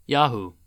Yahoo (/ˈjɑːh/
En-us-yahoo.oga.mp3